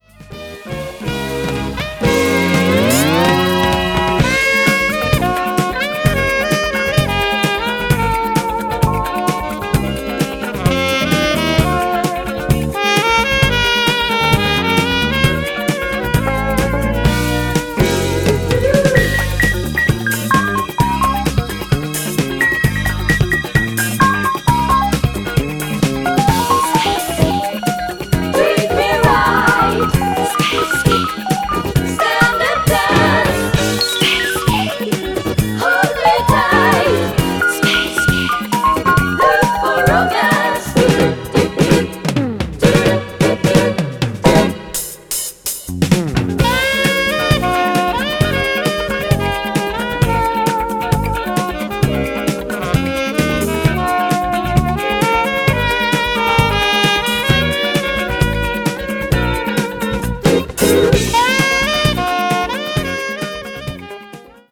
強いて言えば改造後の方が高域の透明感が増した気もする。
当然だが、イコライザアンプ以外の条件は両者同一である。